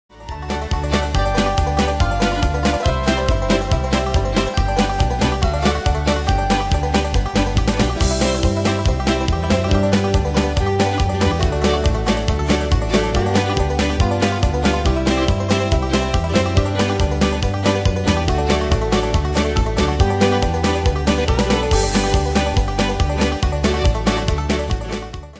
Style: Country Ane/Year: 1999 Tempo:141/165 Durée/Time: 2.24
Danse/Dance: Yodel Cat Id.
Pro Backing Tracks